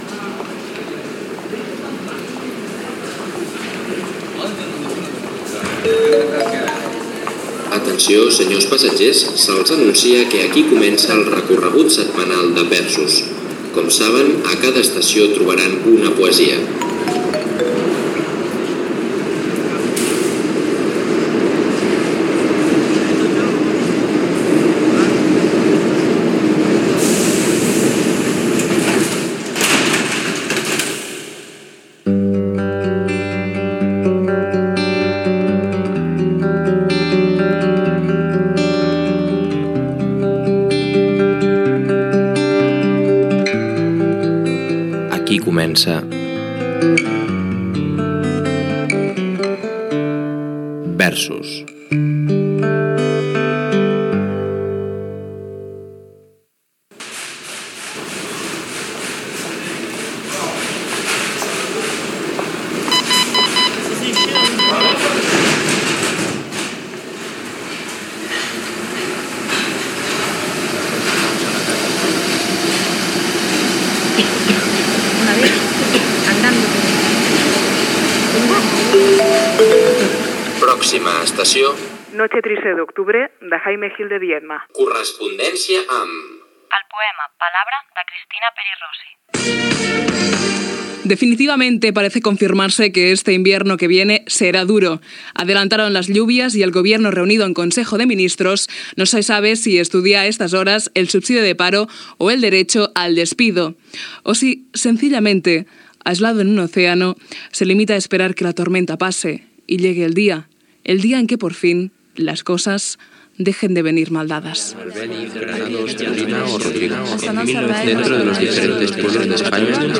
Careta del programa i fragments poètics
Extret del CD Open Radio 2002, celebrat al CCCB.